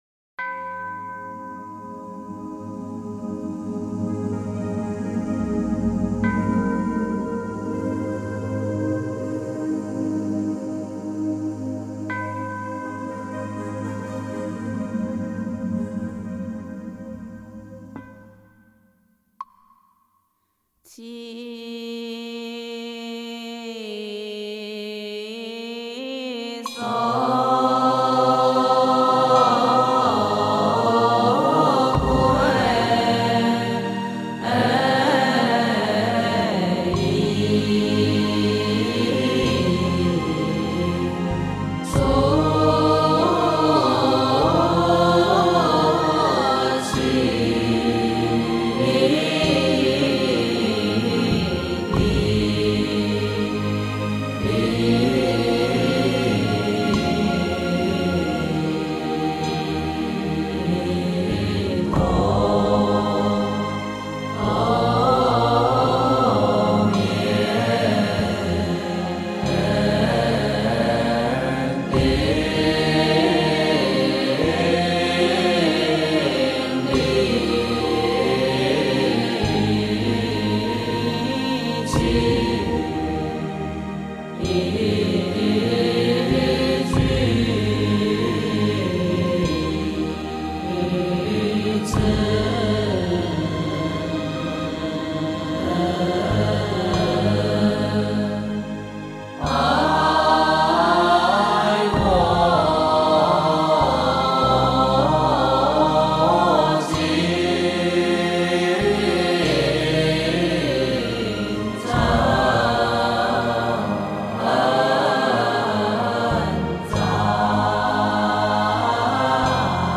梵呗 | 《准提咒》及持咒的十大功德